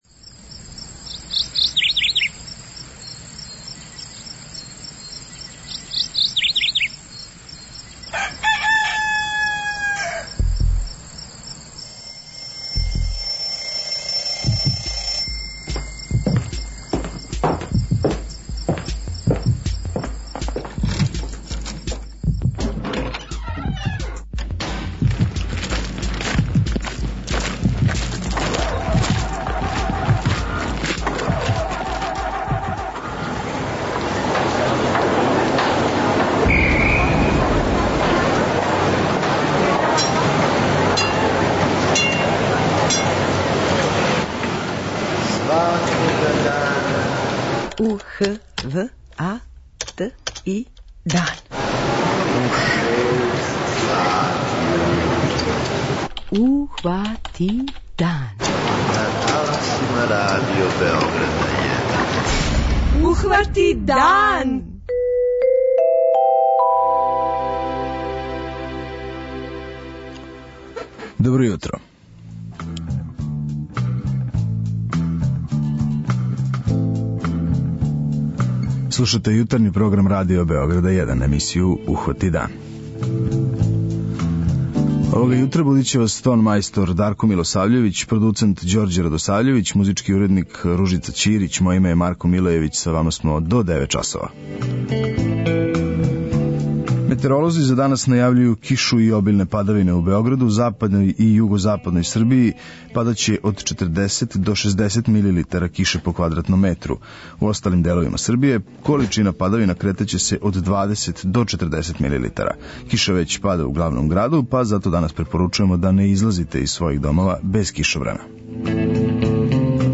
преузми : 43.15 MB Ухвати дан Autor: Група аутора Јутарњи програм Радио Београда 1!